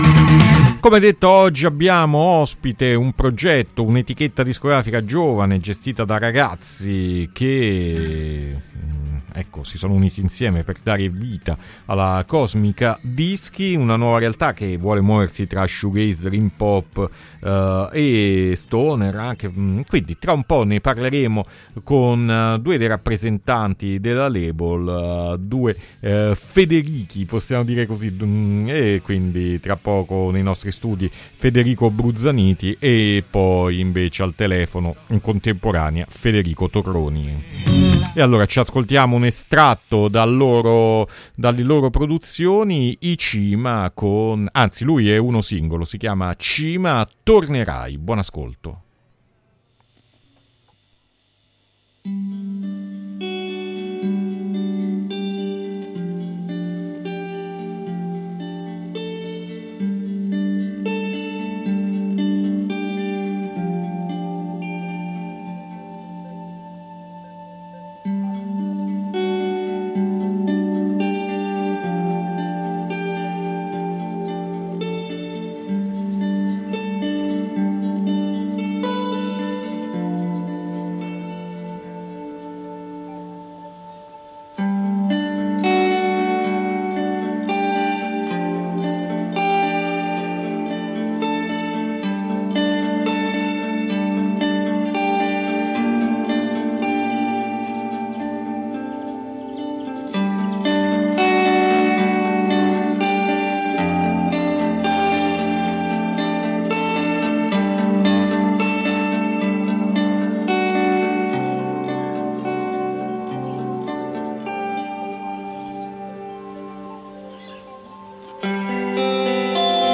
Intervista Kosmica Dischi | Radio Città Aperta